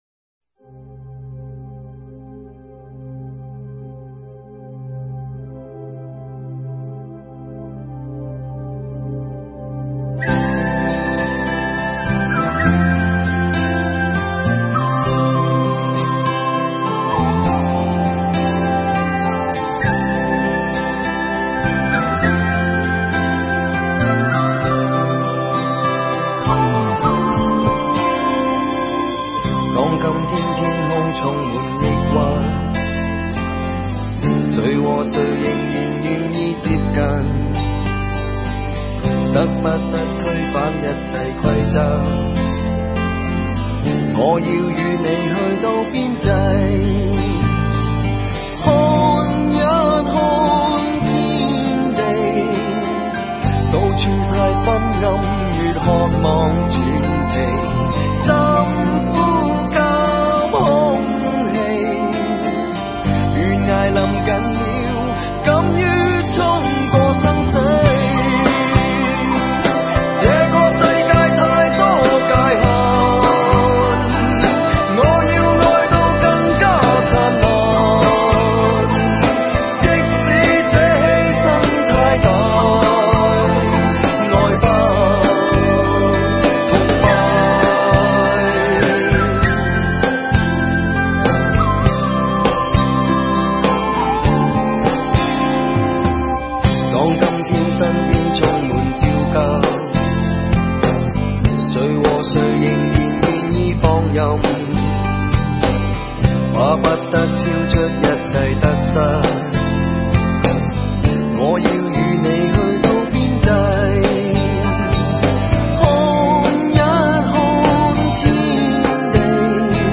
粤语专辑